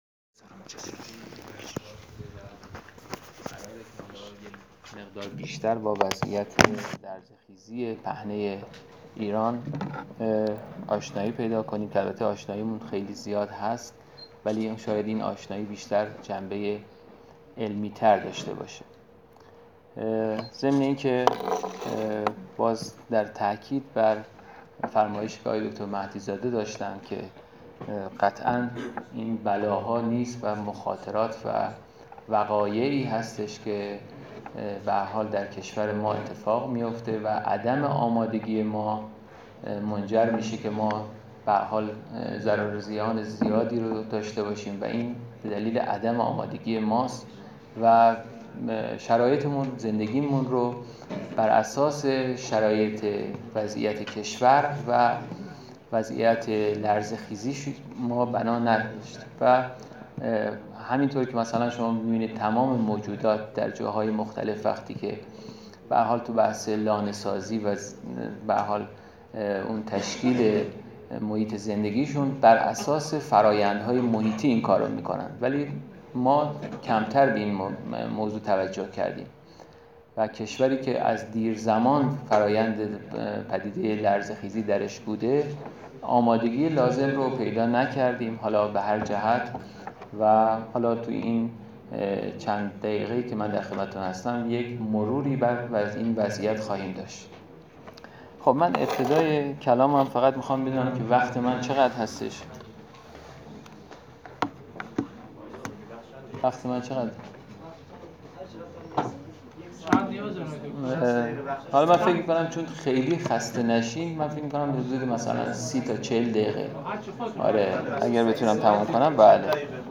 برگزاری همایش علمی زلزله مخربترین بلای طبیعی ایران